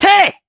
Neraxis-Vox_Attack3_jp.wav